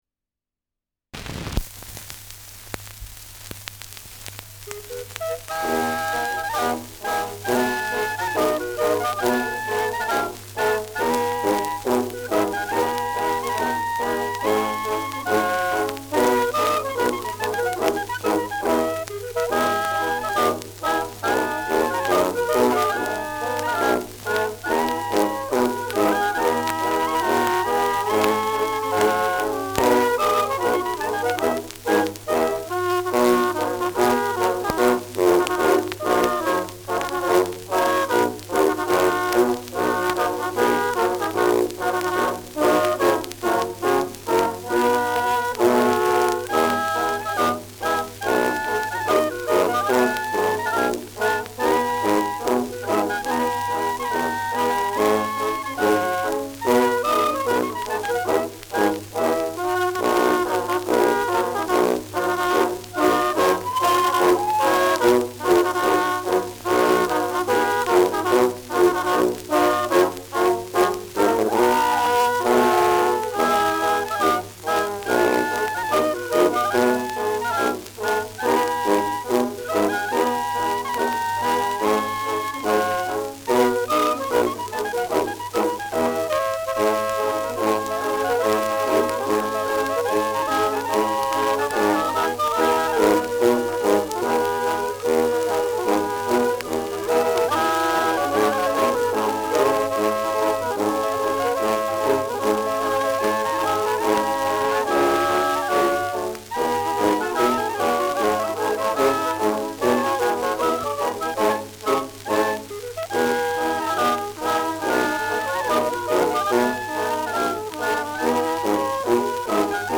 Schellackplatte
Stärkeres Grundrauschen : Durchgehend leichtes bis stärkeres Knacken : Verzerrt an lauteren Stellen
Das bekannte Volkslied ist hier im Trio zu hören.